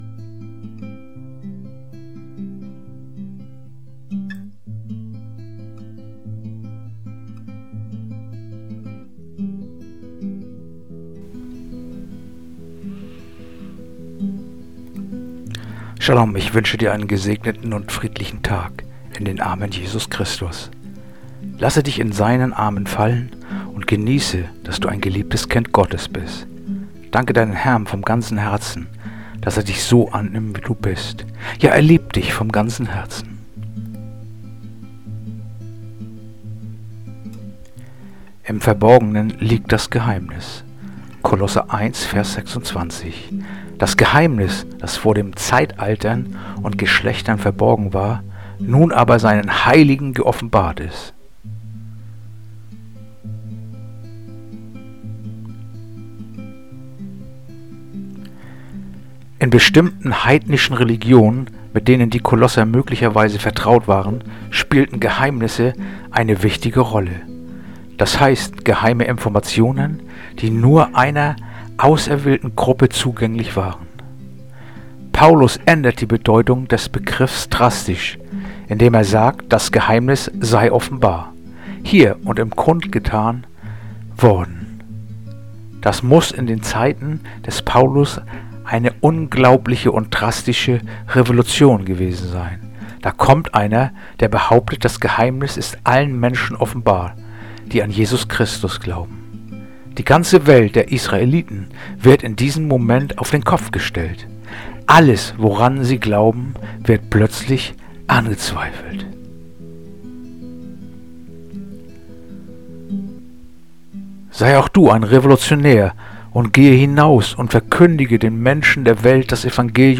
heutige akustische Andacht
Andacht-vom-12.-November-Kolosser-1-26.mp3